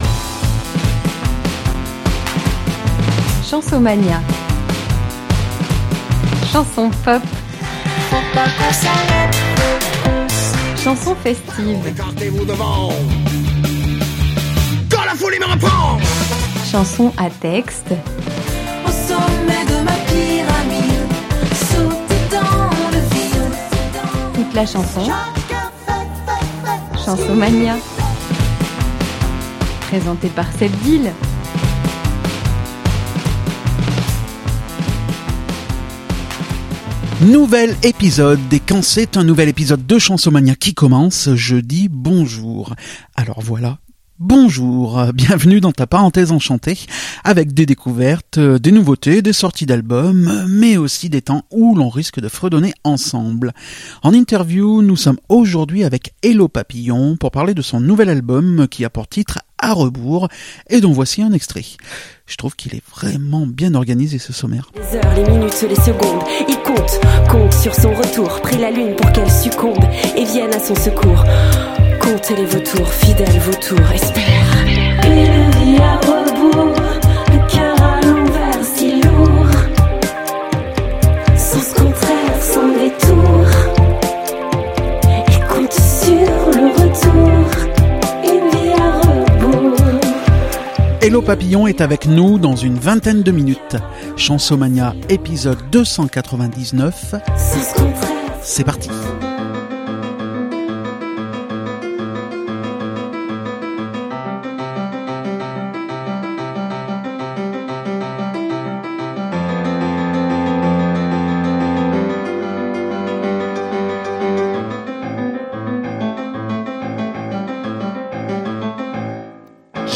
Nous en parlons ensemble, en interview, dans cet épisode.